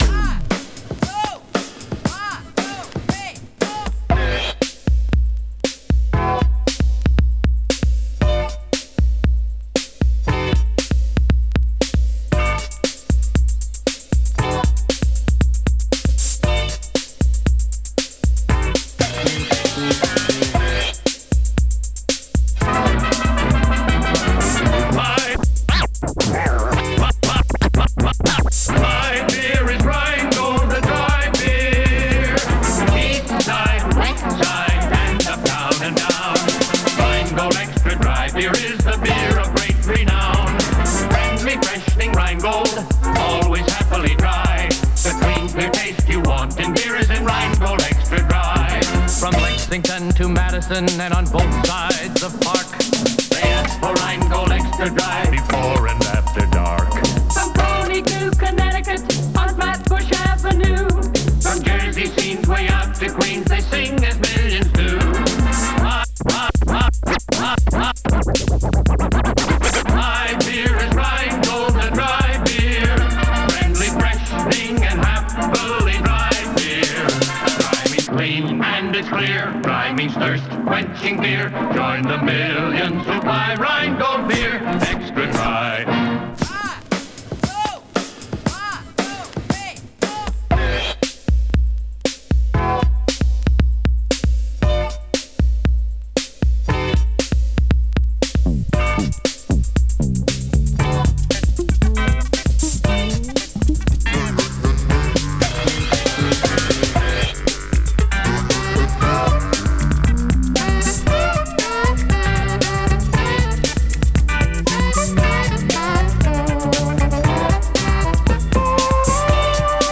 Dance Disco Disco/House Pop Rock